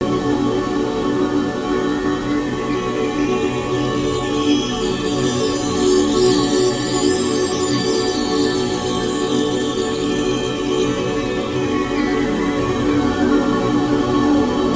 冰冻吉他II
描述：冻结的吉他循环，在加入了法兰盘、延迟和激励后变得更加奇怪。
Tag: 65 bpm Ambient Loops Guitar Acoustic Loops 2.49 MB wav Key : B